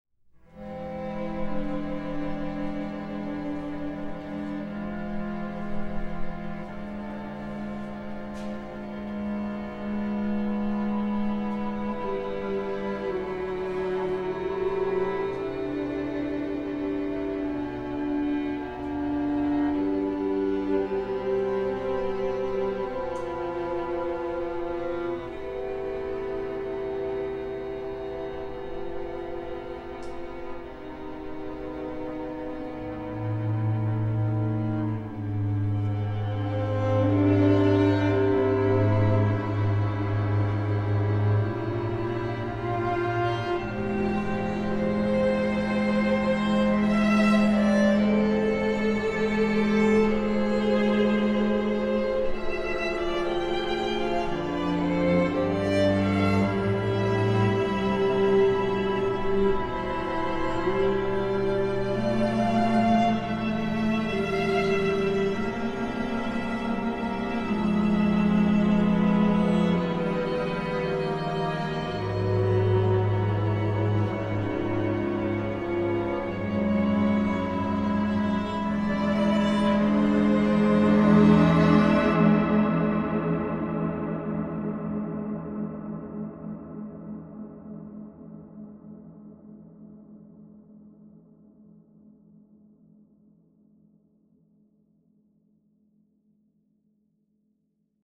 ORCHESTRAL
string quartet iv /original orchestration  01:38